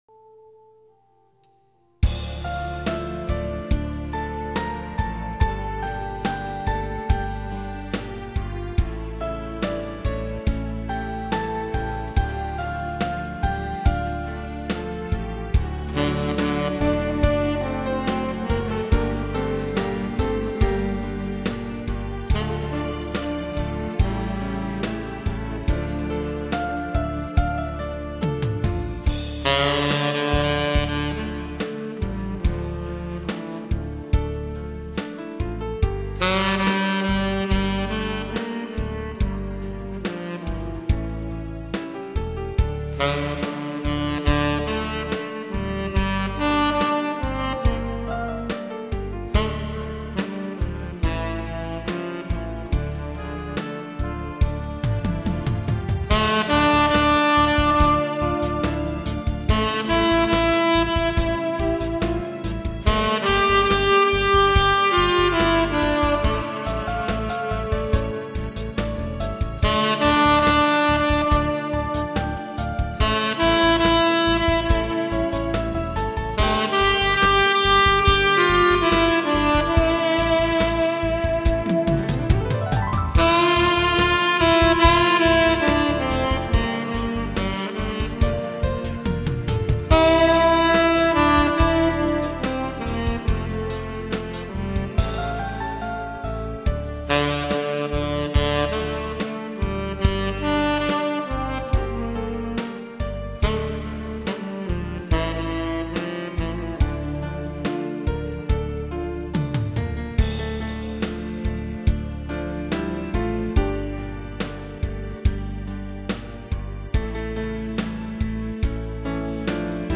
테너연주입니다